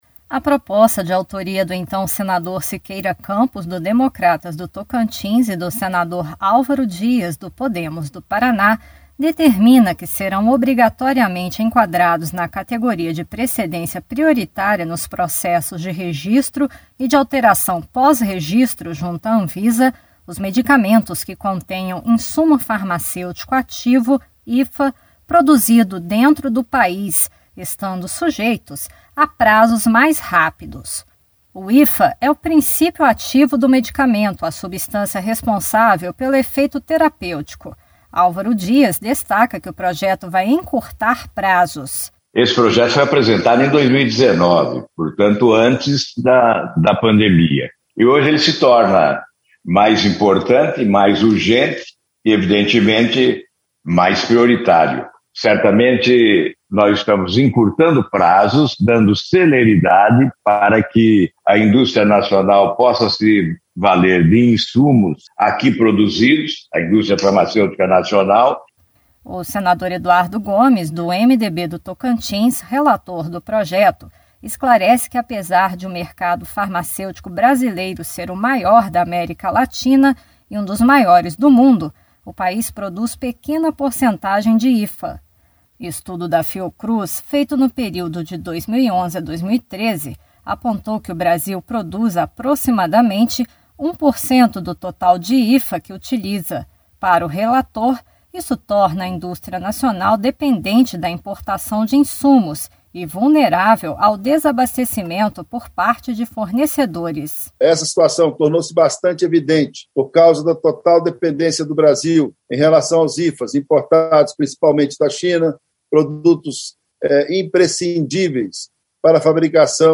Senador Alvaro Dias
Senador Eduardo Gomes